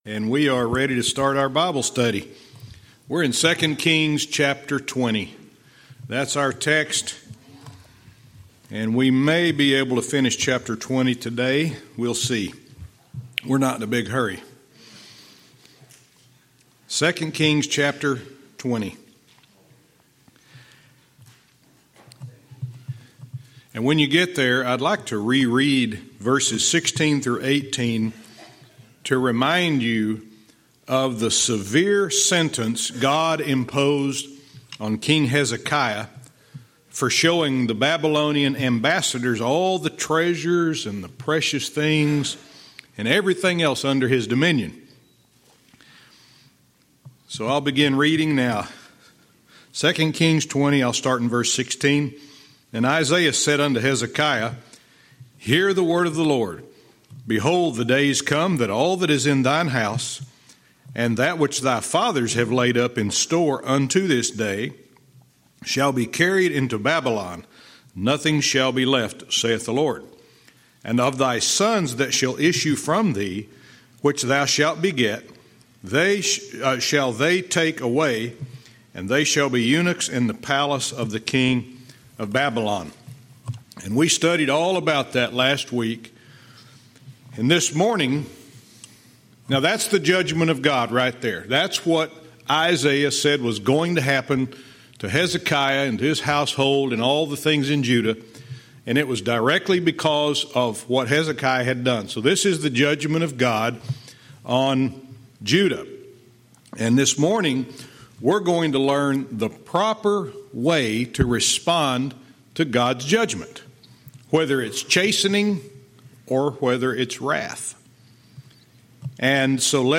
Verse by verse teaching - 2 Kings 20:19-20